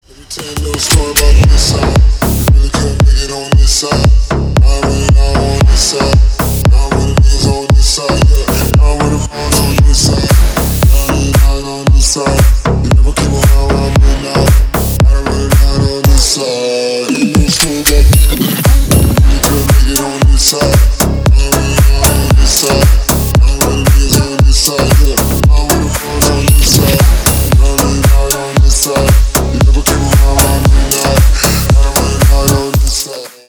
бесплатный рингтон в виде самого яркого фрагмента из песни
Поп Музыка # Танцевальные
клубные # громкие